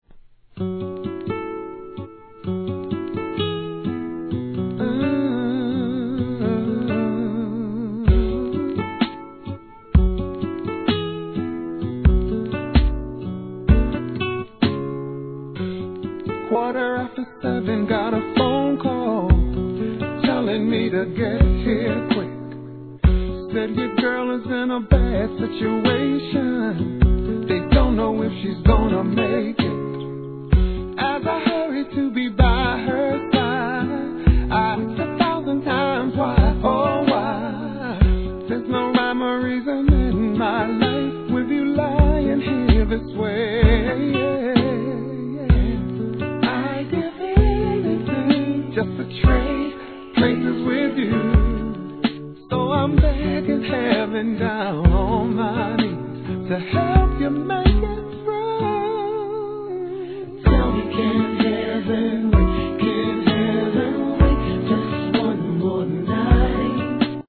HIP HOP/R&B
アコースティックの哀愁たっぷりのスローで歌い上げるウィスパーボイスにどっぷり浸れます。